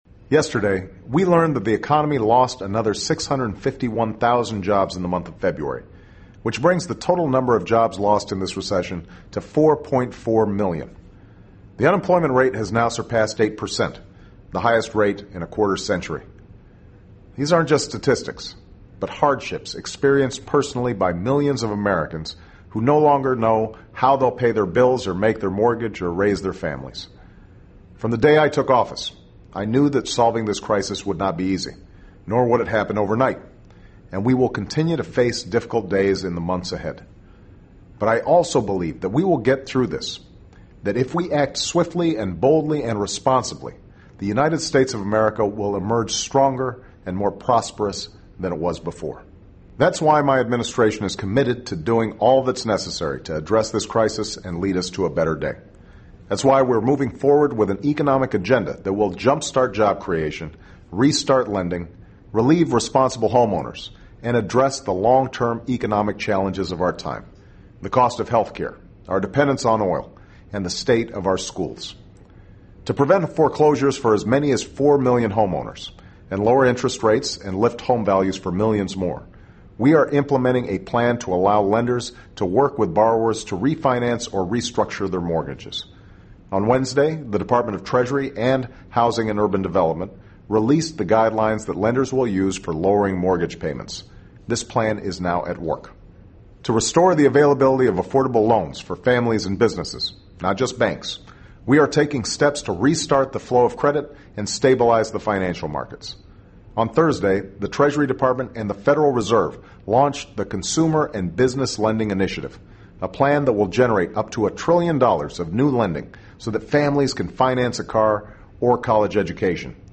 【美国总统电台演说】2009-03-07 听力文件下载—在线英语听力室